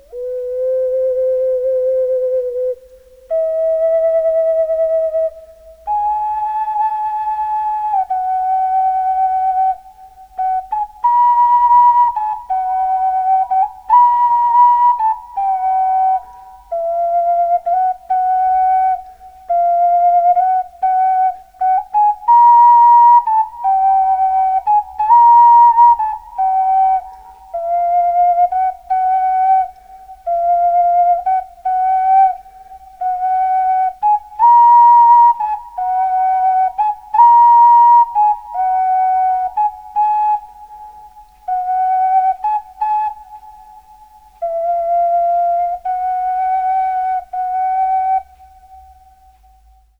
Okarina Floete, Soloversion.